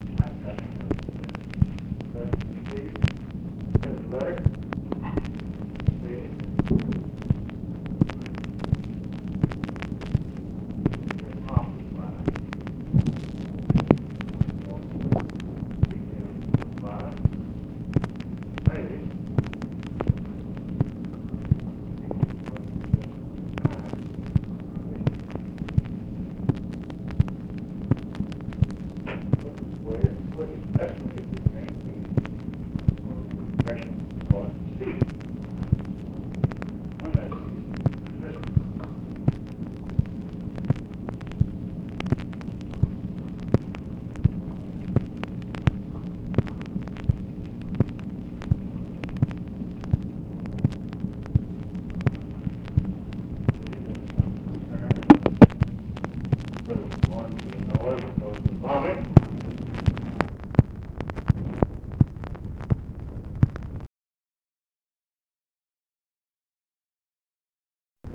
ALMOST INAUDIBLE DISCUSSION OF GHANA'S PRESIDENT NKRUMAH'S UPCOMING TRIP TO HANOI, CONCERNS ABOUT HIS SAFETY DUE TO US BOMBING
Secret White House Tapes